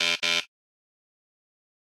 airlock_deny.ogg